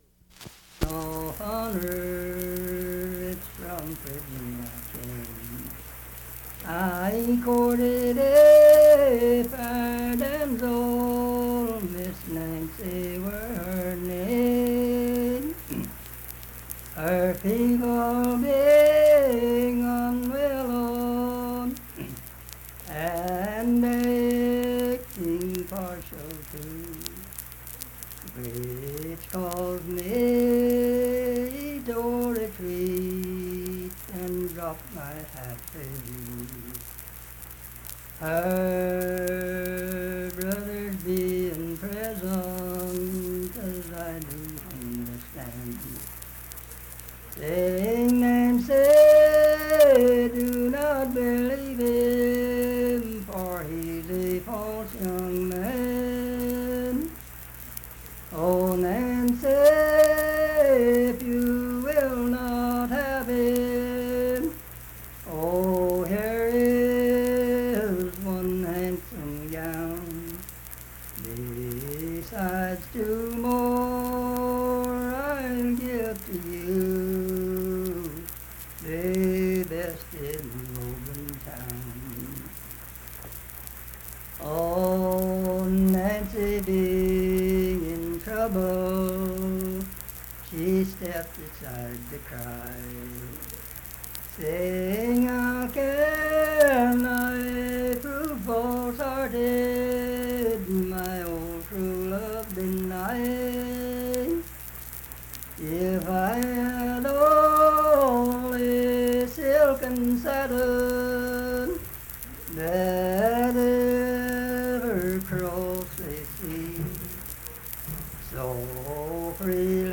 Unaccompanied vocal music
Verse-refrain, 6(8).
Voice (sung)